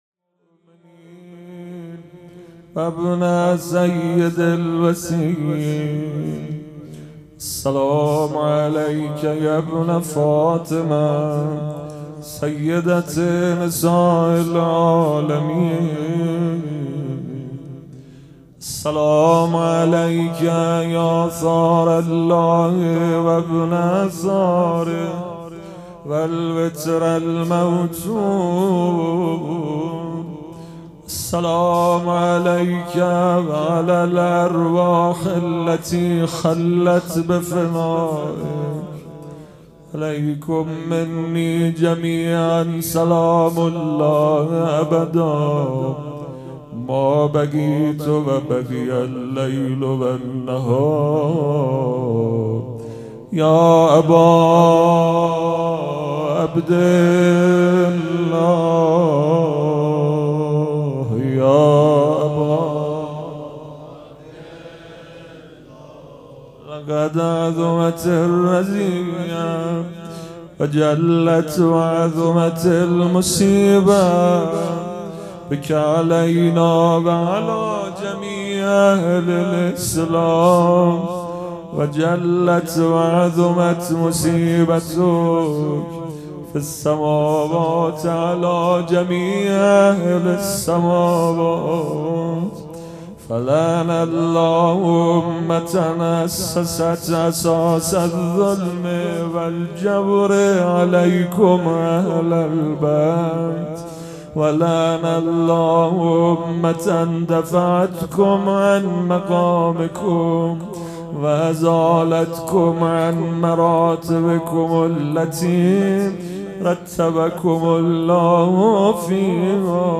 پیش منبر
شهادت امام صادق (ع)